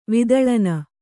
♪ vidaḷana